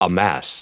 Prononciation du mot amass en anglais (fichier audio)